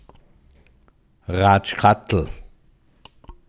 einer oder eine die immer alles weitererzählen müssen Reith im Alpbachtal